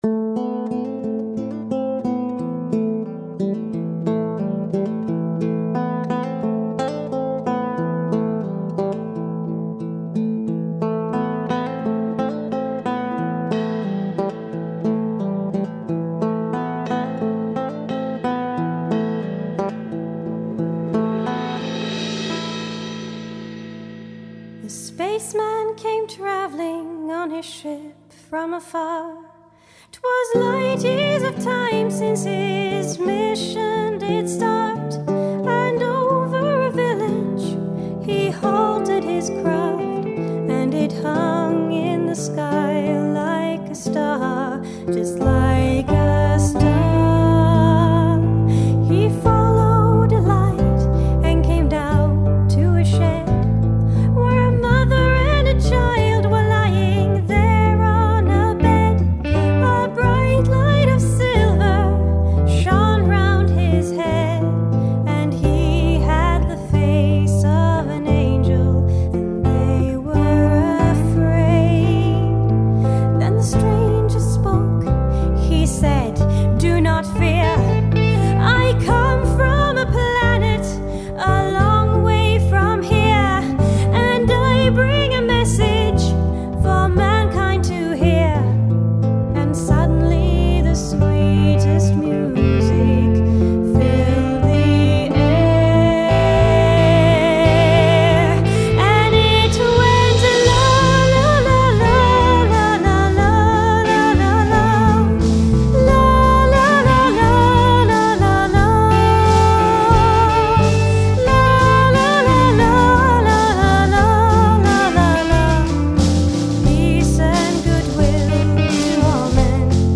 Lead Guitar
Rhythm Guitar
Bass
Drums
Digital Effects
Additional Vocals